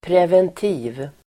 Ladda ner uttalet
Uttal: [pr'ev:enti:v (el. -'i:v)]